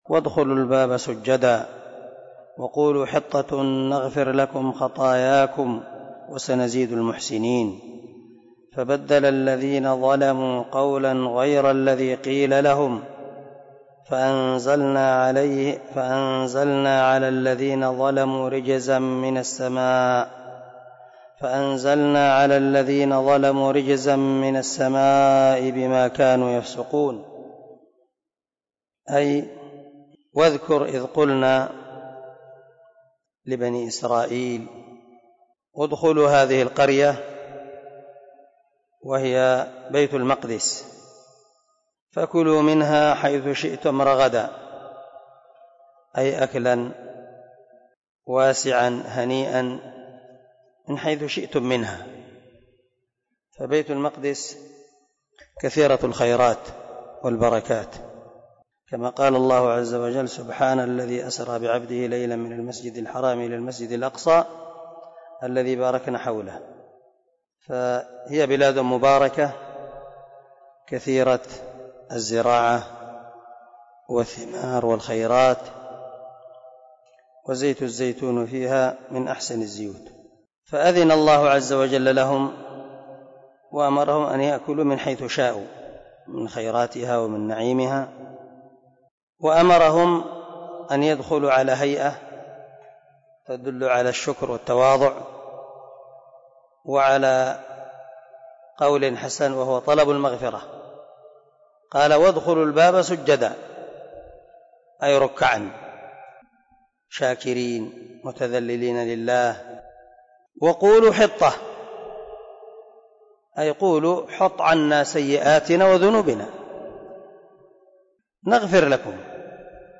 030الدرس 20 تفسير آية ( 58 - 60 ) من سورة البقرة من تفسير القران الكريم مع قراءة لتفسير السعدي